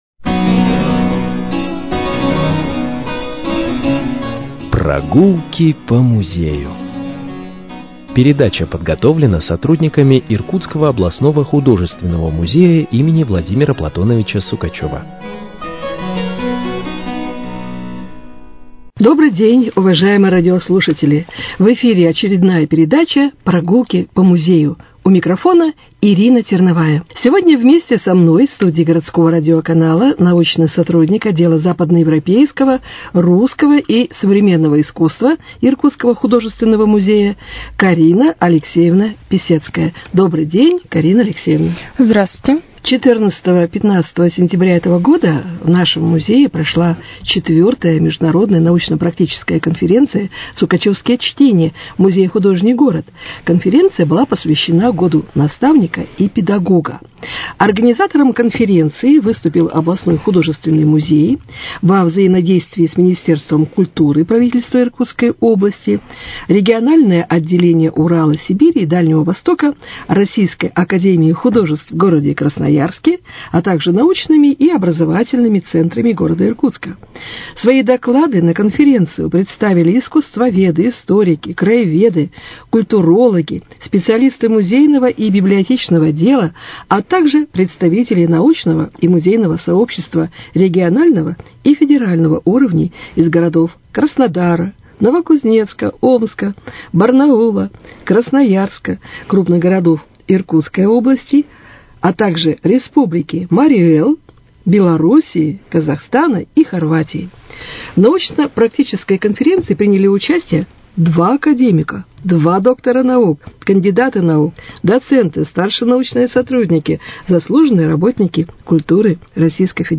Прогулки по музею: Беседа